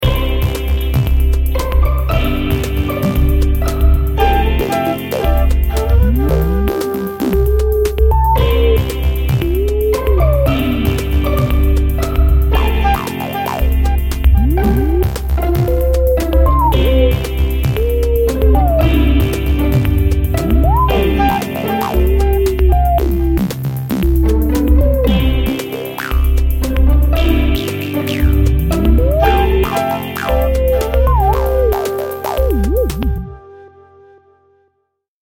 Style: Contemporary Mix